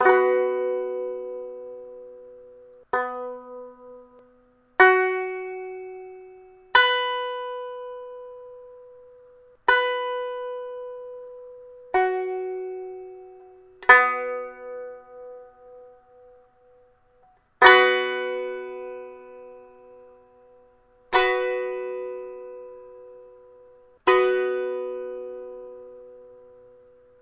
弦乐棒 " 弦乐棒2音阶棒材
描述：用第二品格的琴弦和弹拨16bit用Blue Snoball麦克风录制
标签： 俯仰 乱弹 strumstick 乐器 吉他 strumstick 拔毛 笔记 样品
声道立体声